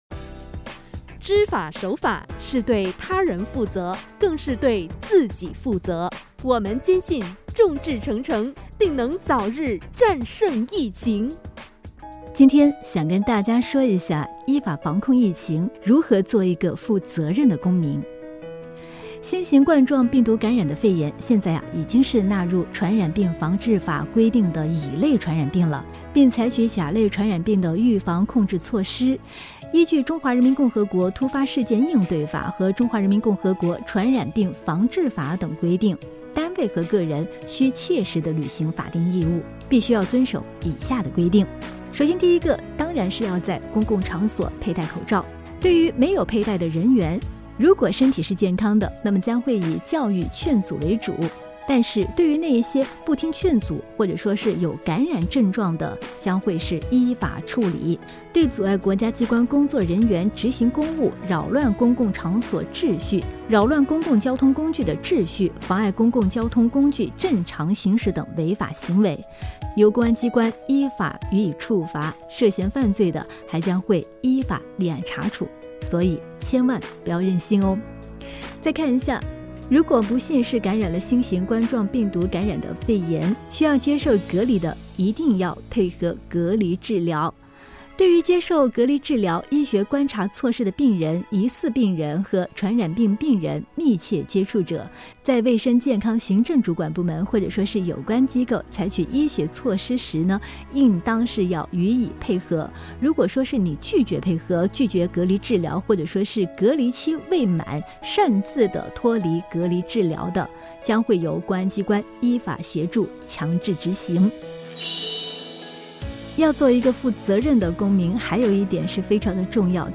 防控疫情普法广播节目 2020-02-12 16:24:56 来源： 本网 发布机构： 本网 【字体： 大 中 小 】 防控疫情普法广播节目清样（普通话版）.mp3 扫一扫在手机打开当前页 版权声明： 凡本网注明"稿件来源：台山政府网"的所有文字、图片和音视频稿件，版权均属台山政府网所有，任何媒体、网站或个人未经本网协议授权不得复制或转载。